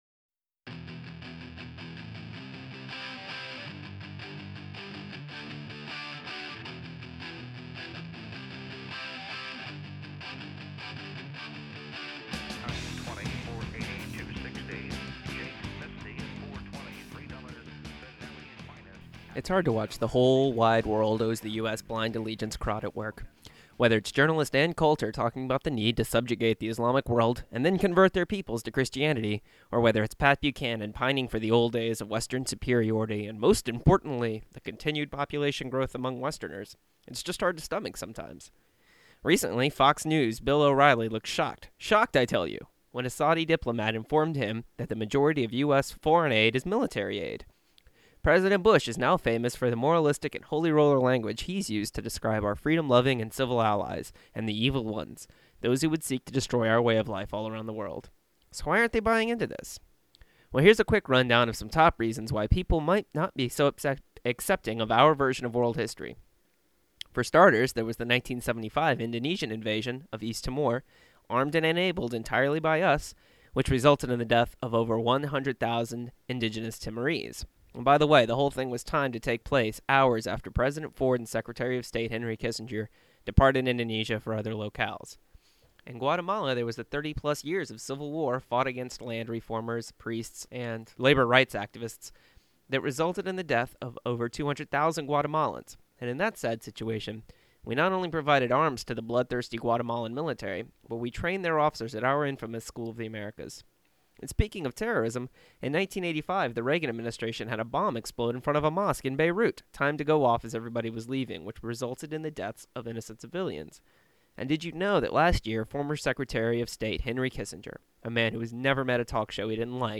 This is an editorial piece I wrote for the Urbana-Champaign Independent Media Center News Hour for 3/17. It's about how the shock and denial in the US over how people perceive us. In the background is "Anthem for a New Tomorrow" by Screeching Weasel.